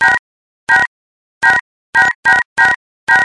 描述：立刻播放3个座机电话按钮的声音。
Tag: 电话 手机按键 讨厌 手机